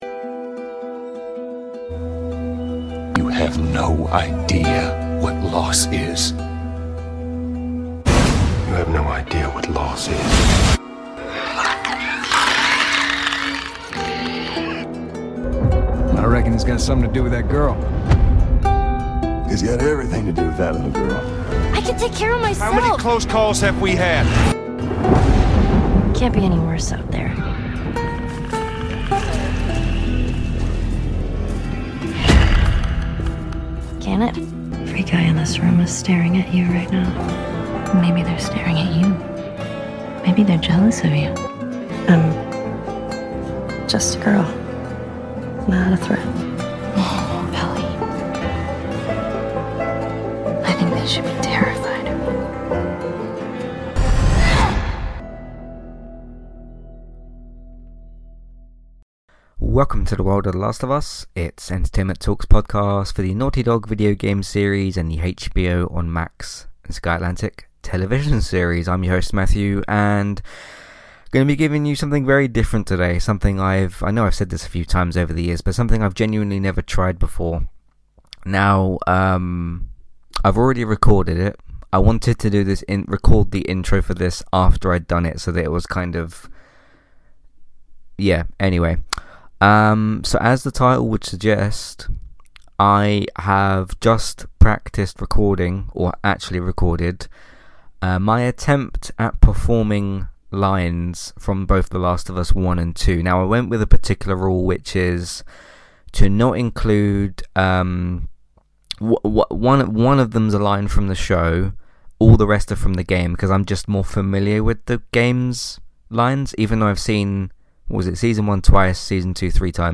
Back with another The Last Of Us podcast, this time performing lines from the 2 games and the TV series